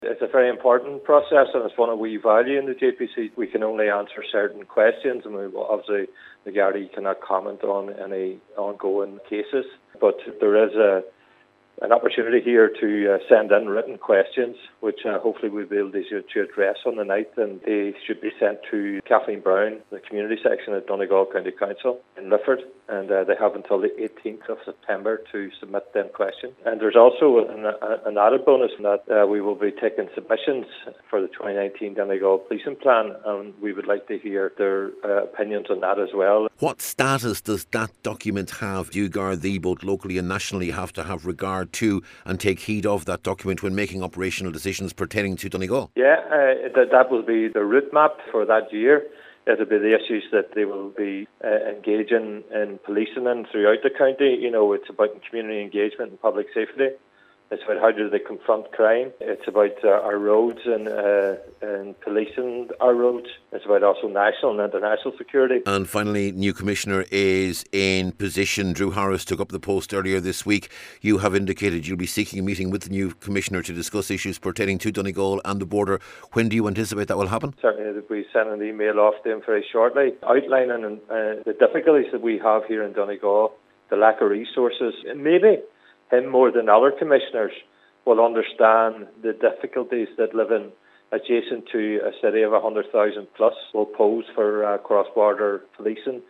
JPC Chair Cllr Gerry Mc Monagle says the Policing Plan will be a very significant document: